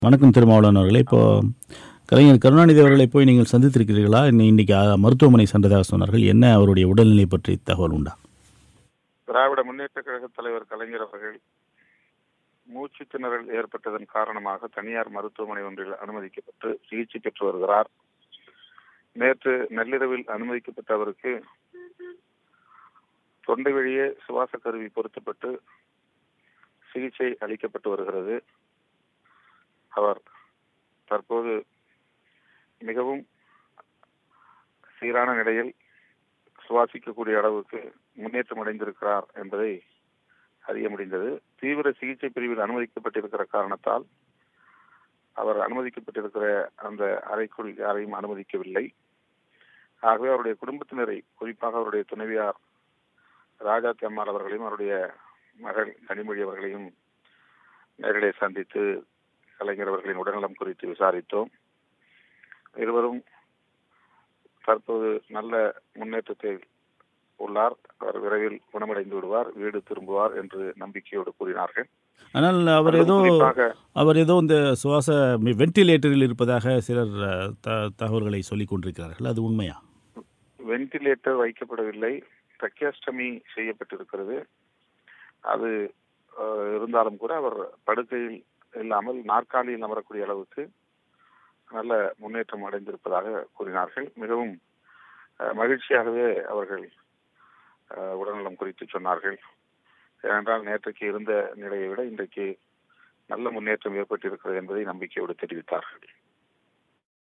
சுவாச பிரச்சனைக்காக மருத்துவமனையில் அனுமதிக்கப்பட்டுள்ள திமுக தலைவர் கருணாநிதியை சந்தித்த விசிக தலைவர் தொல்.திருமாவளவனின் பேட்டி.